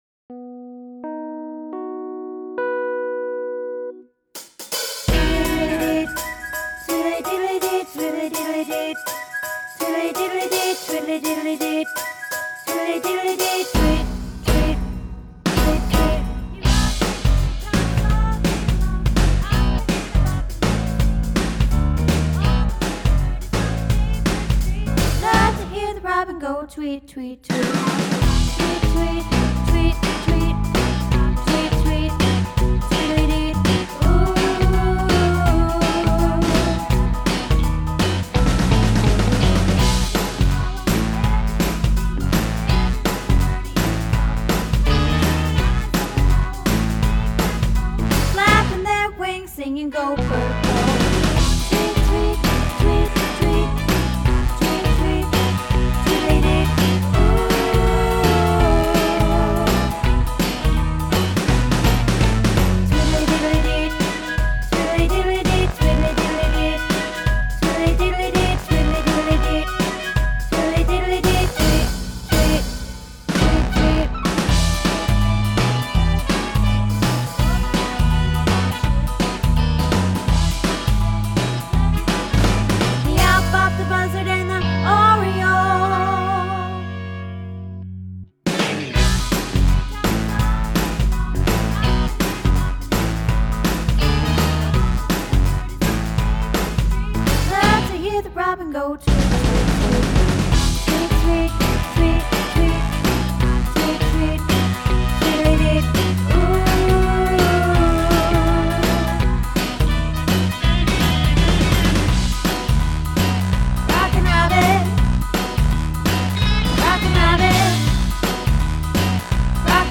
Rockin Robin - Tenor